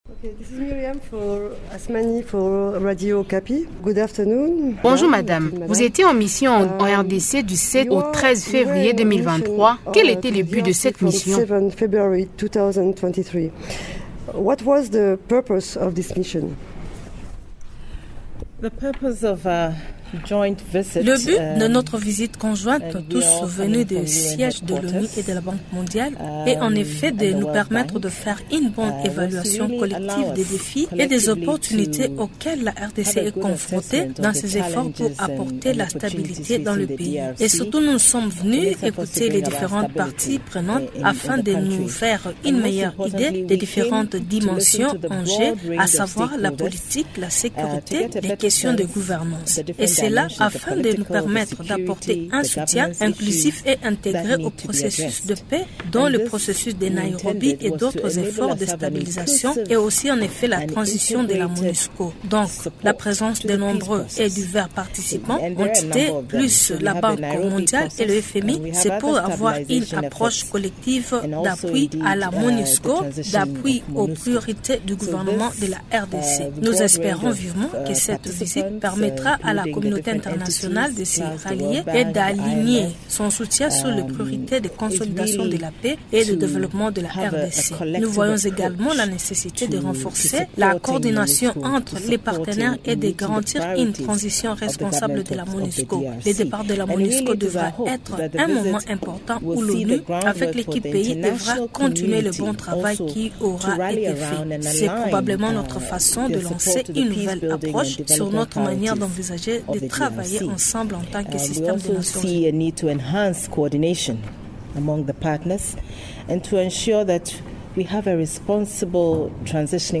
Peu avant de quitter Kinshasa, Martha Pobee, la Sous-Secrétaire générale pour l’Afrique et Elizabeth Spehar, Sous-Secrétaire générale en charge de l’appui à la paix répondent aux questions de Radio Okapi et de l’AFP.